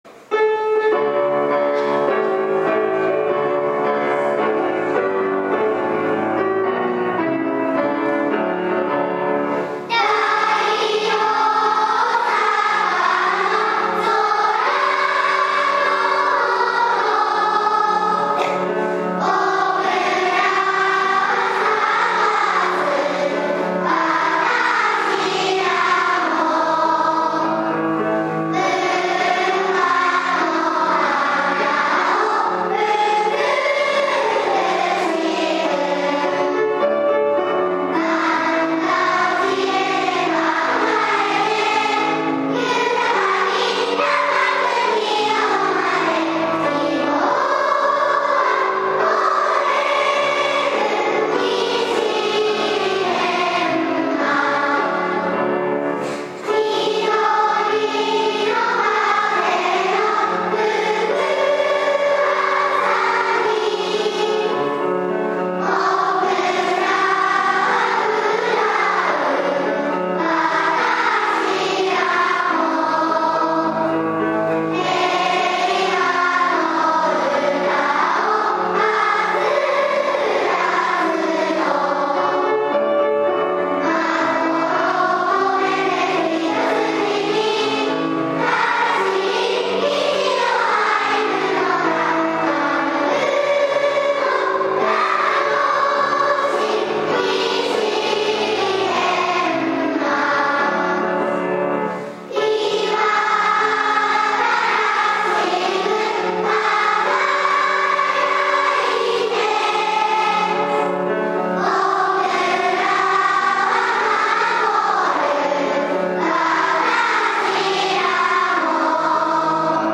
記念式典
「校歌斉唱」